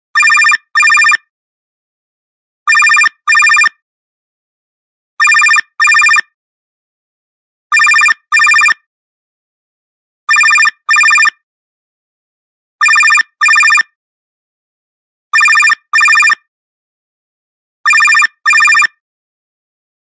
Categories Electronic Ringtones